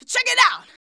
CHECK IT.wav